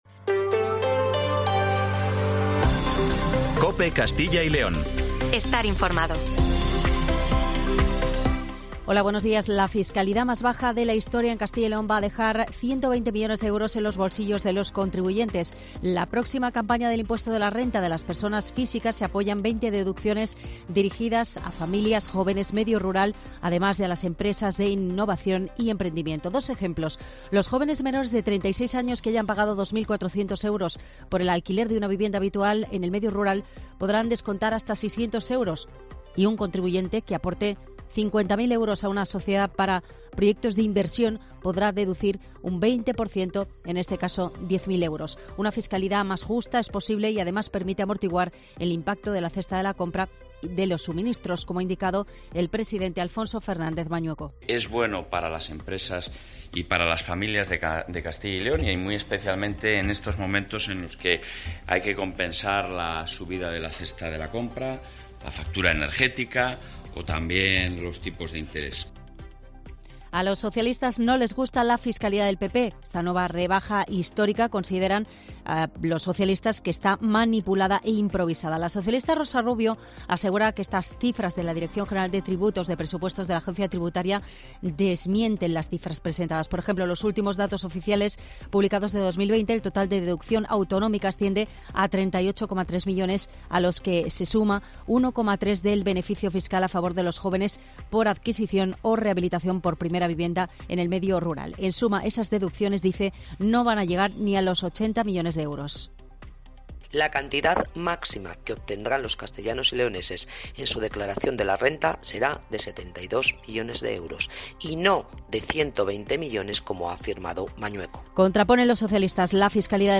Informativo Matinal 7:20